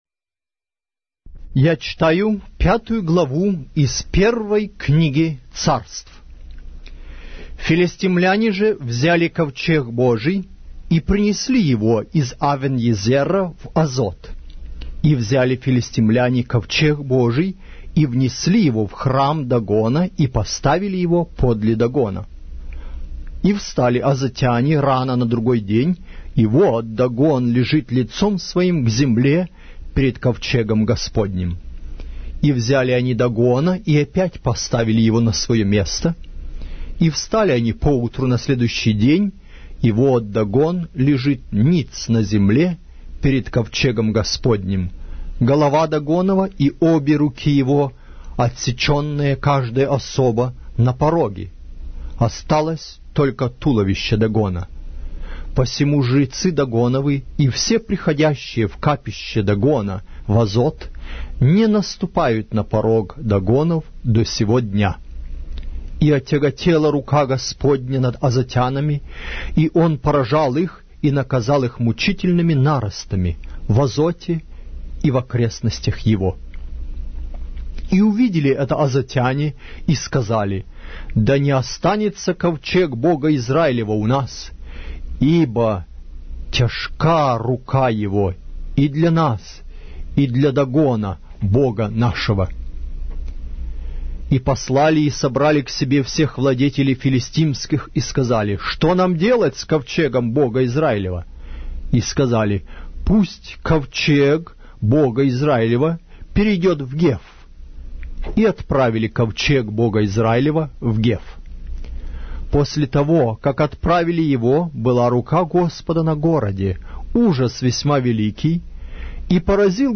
Глава русской Библии с аудио повествования - 1 Samuel, chapter 5 of the Holy Bible in Russian language